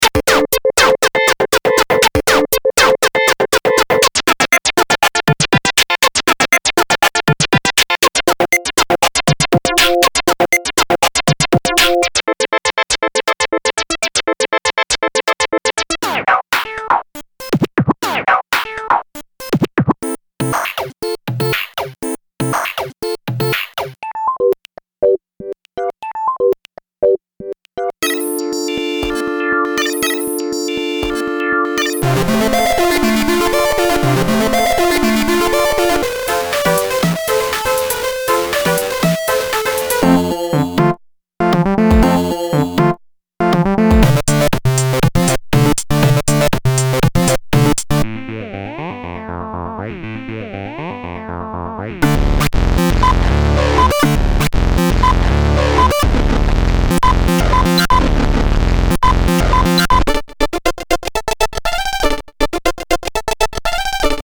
teenage-engineering-–-pocket-operator-16.mp3